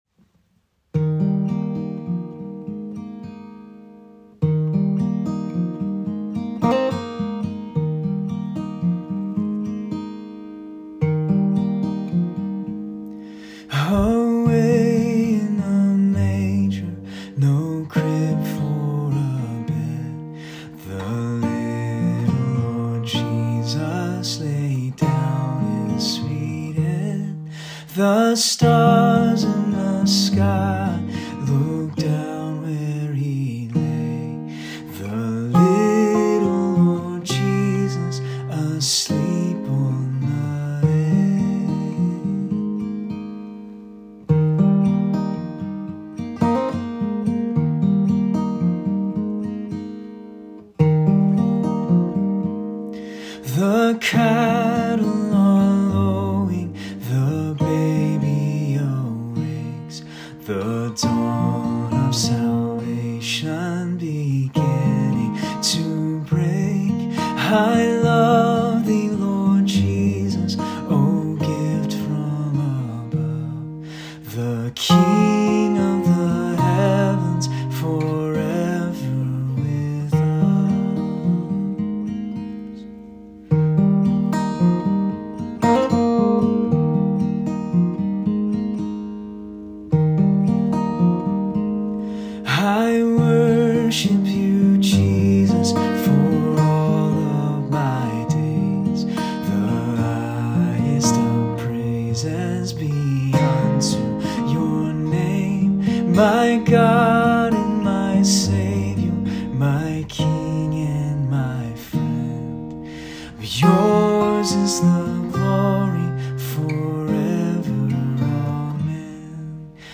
Christmas Celebration 2023 (Kid's Choir Songs)
Kids Choir Songs (audio)
K3 - 1st Grade: